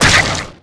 SFX monster_hit_slime.wav